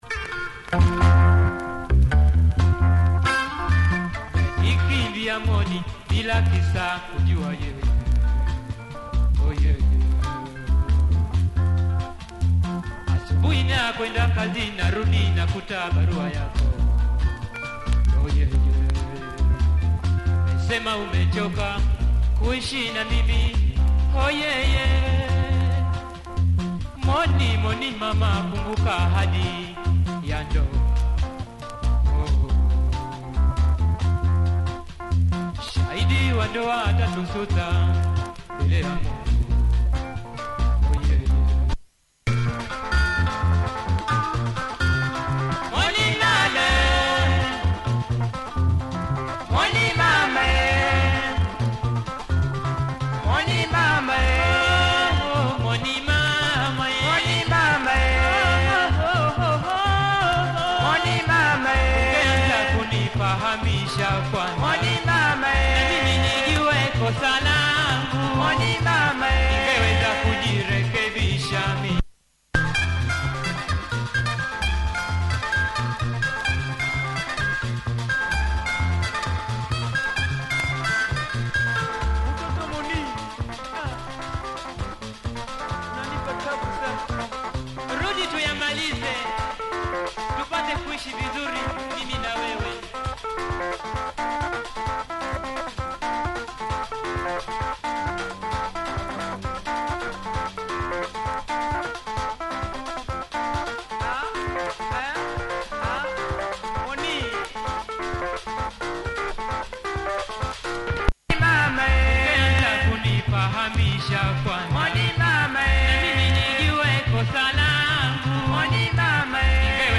nice breakdown! https